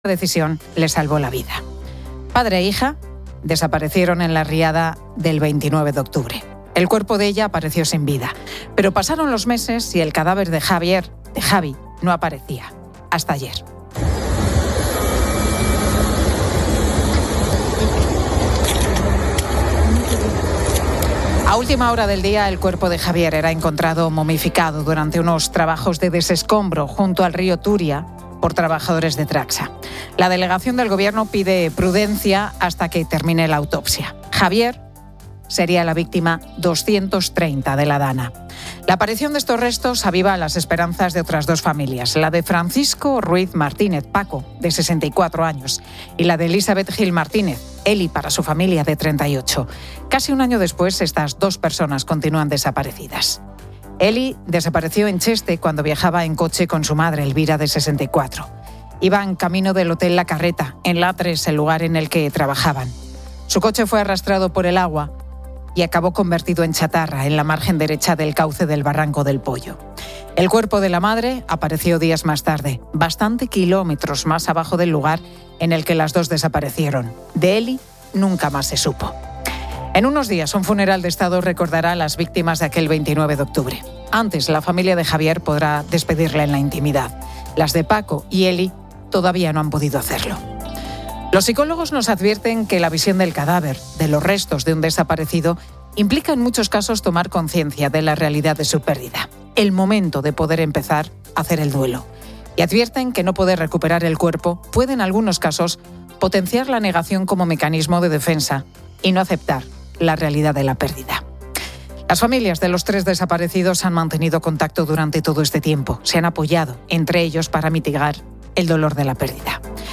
Se debate sobre la subida de precios de los alimentos. Se destaca que la subida del precio de la carne de ternera se debe a la reducción del ganado y al aumento de la demanda externa.